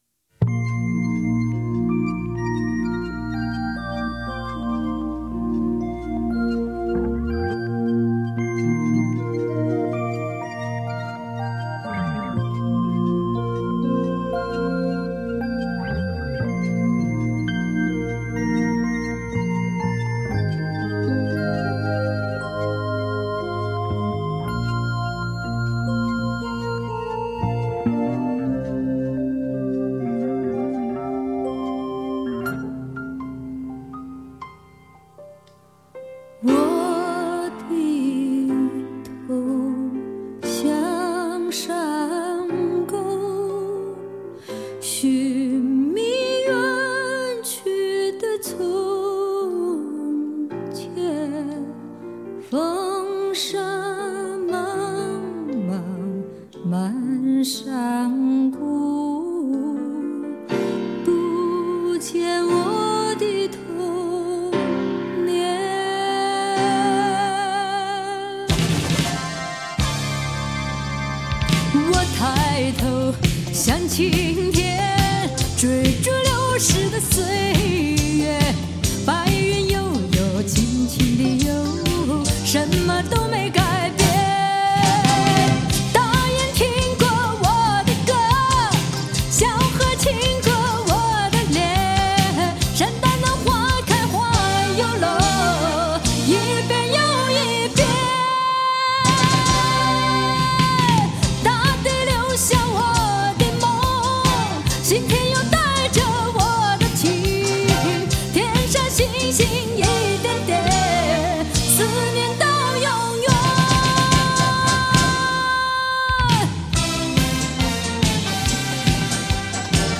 突然出场的西北风，吹得有点意外。